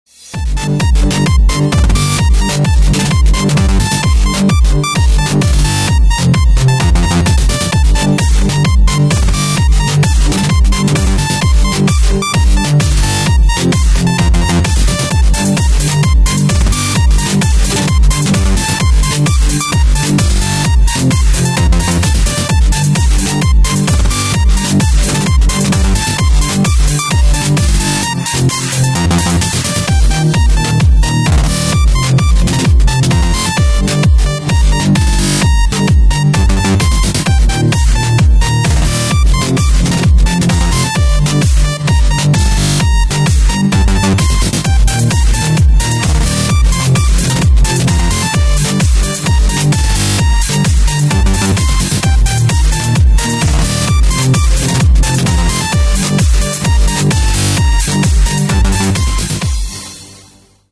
• Качество: 128, Stereo
громкие
dance
Electronic
EDM
без слов
electro house